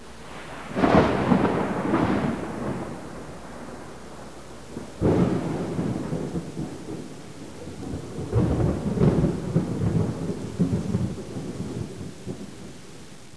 Thunder2.wav